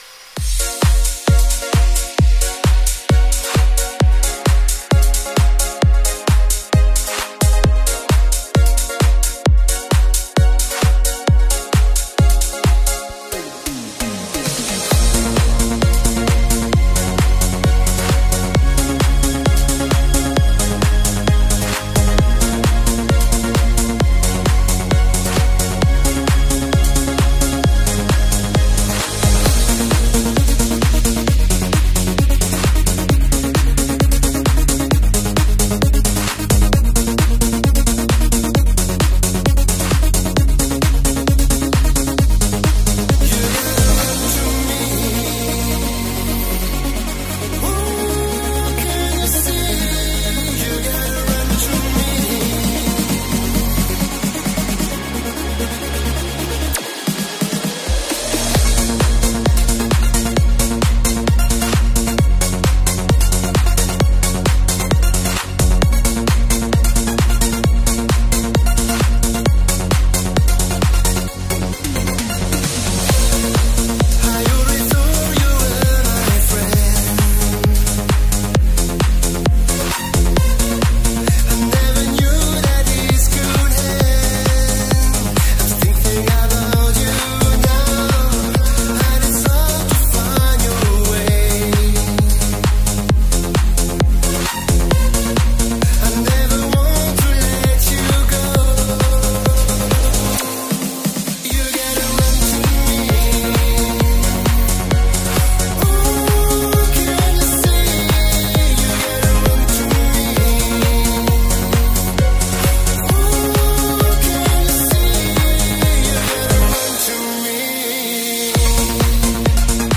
EuroDance_MegaMix___.mp3